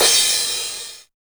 909 CRASH.wav